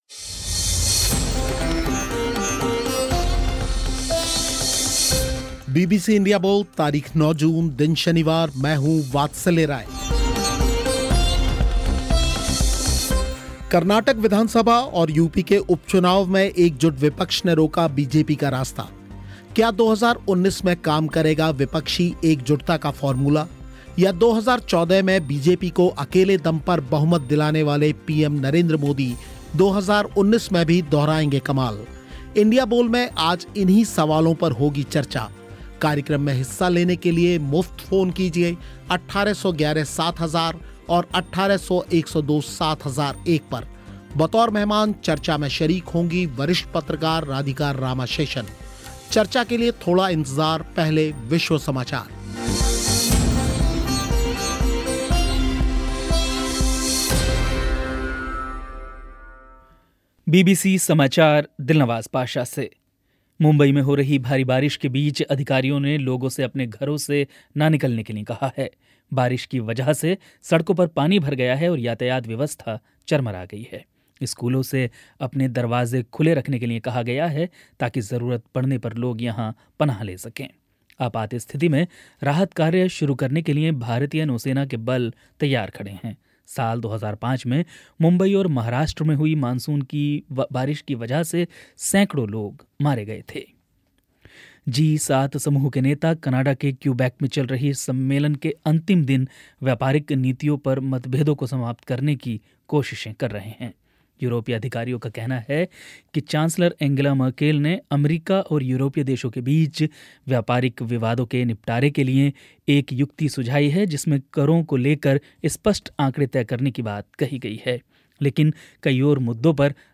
इंडिया बोल में इन सवालों पर हुई चर्चा
और साथ जुड़े श्रोता